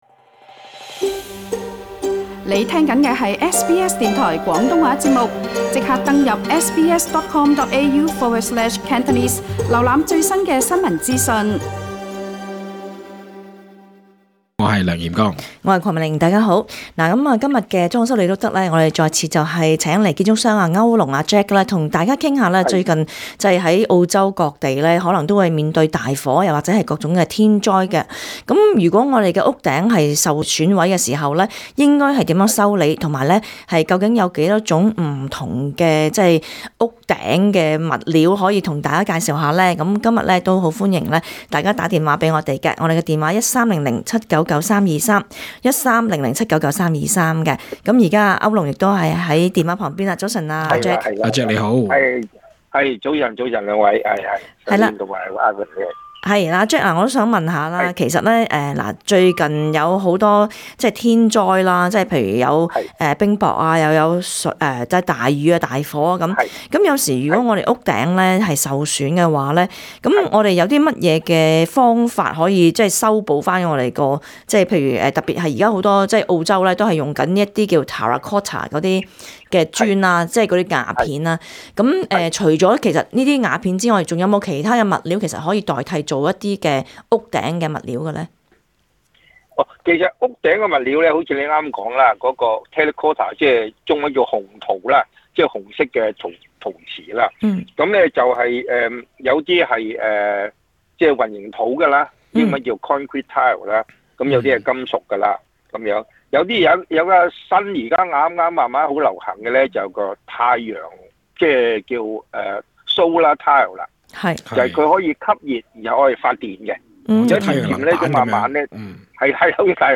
本節目內嘉賓及聽眾意見並不代表本台立場 READ MORE 【裝修你都得】你的房屋是否做好防治蟲害的工作？